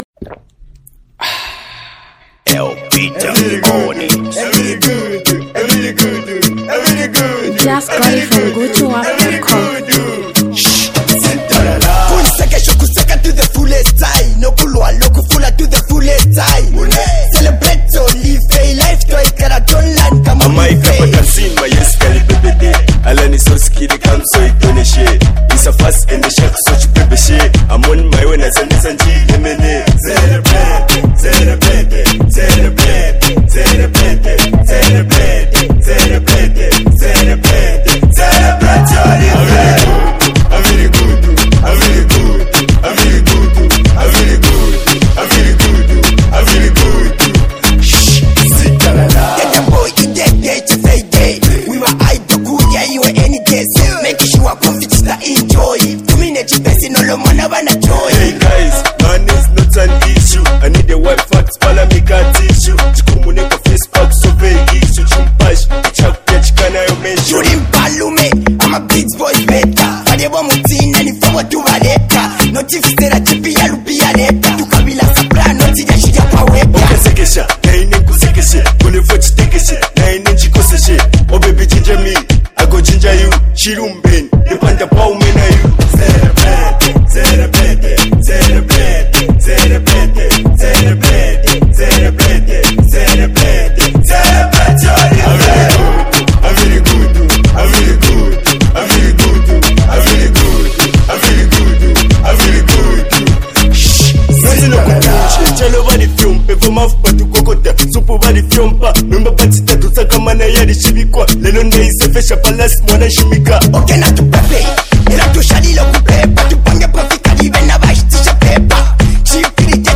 duo hardcore rappers
dancehall
street anthem